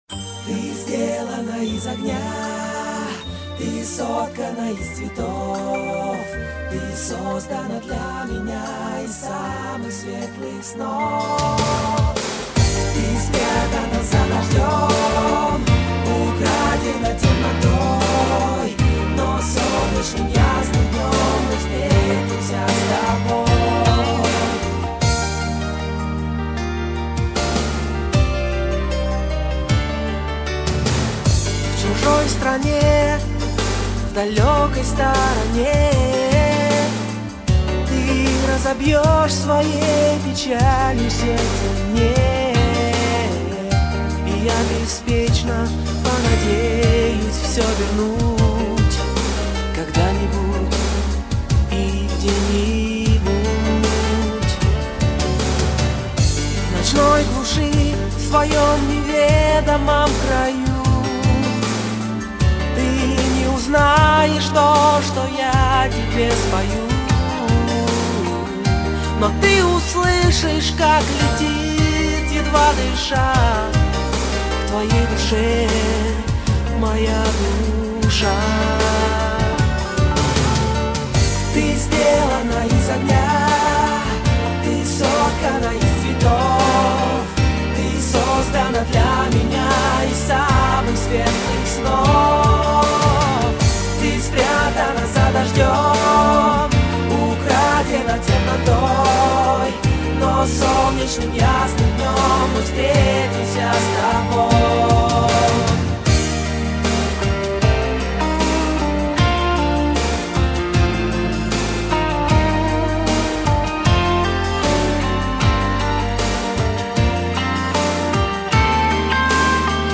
русские медляки , медленные песни